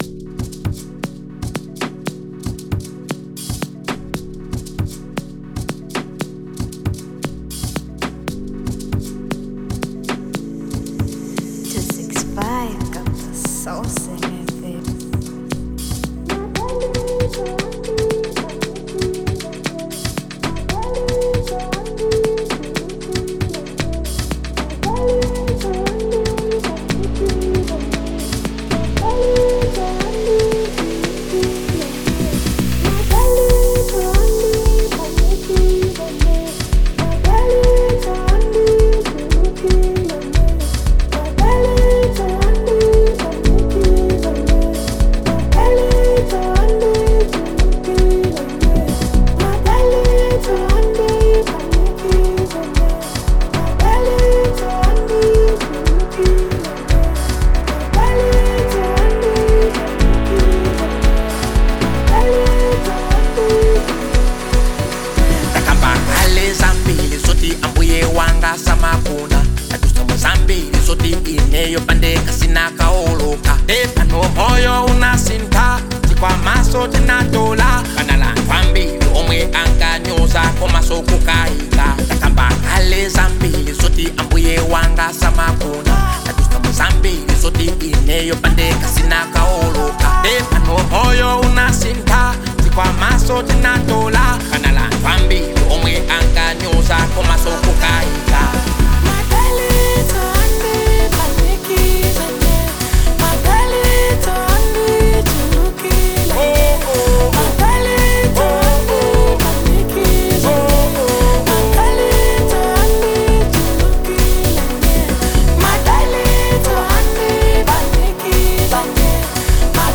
Genre : Amapiano
is a soulful and uplifting track